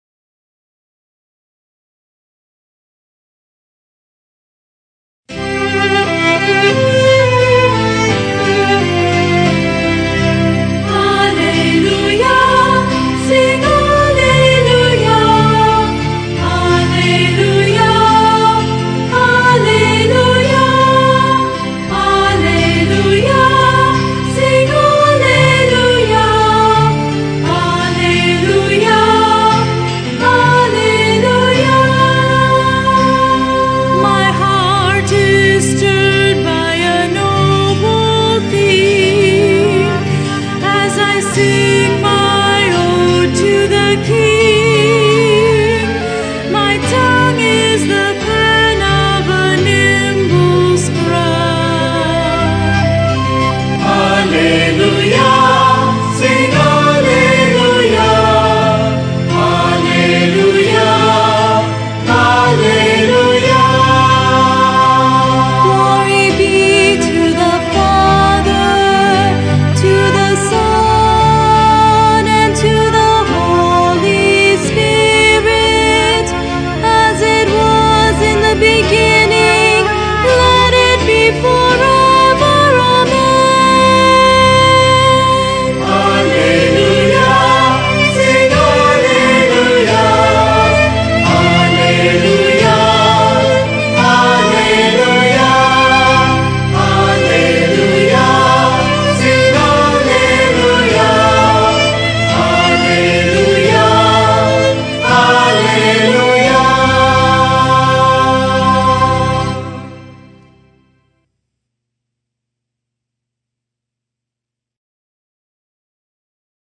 and chorus